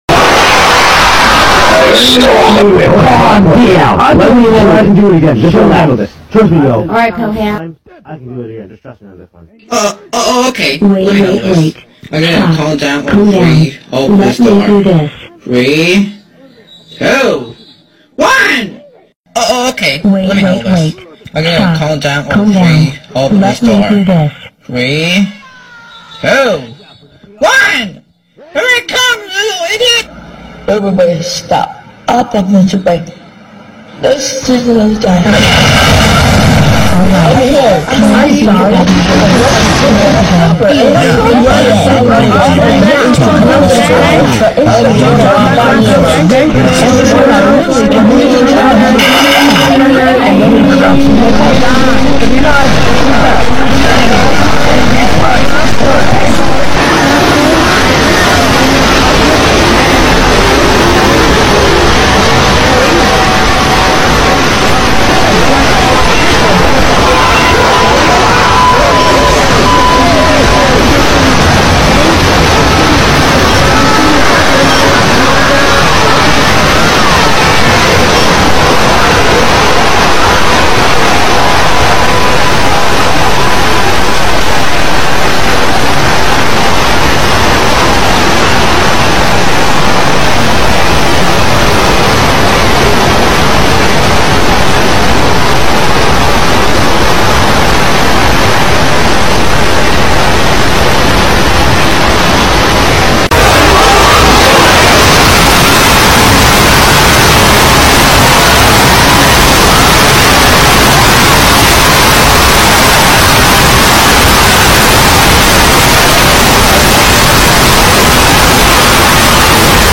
[‼‼‼‼⚠⚠⚠⚠☢☢☢☢!!!!!WARNING VOLMUE ALERT TURN DOWN sound effects free download